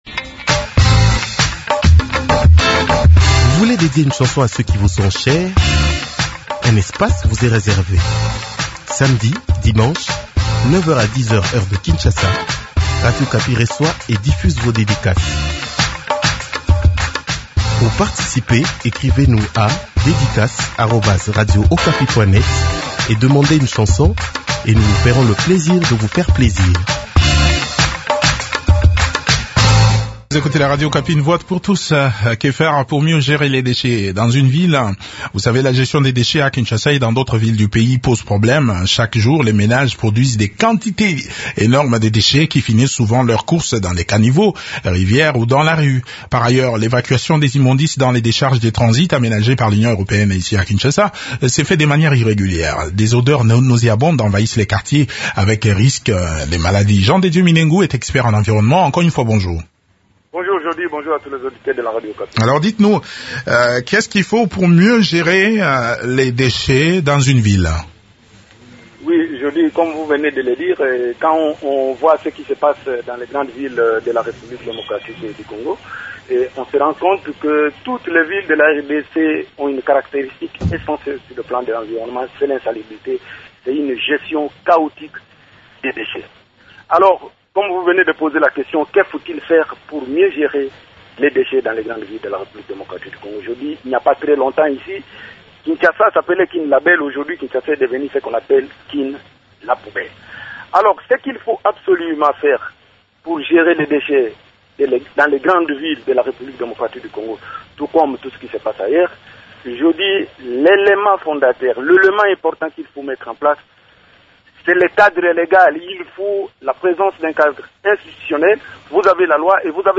expert en environnement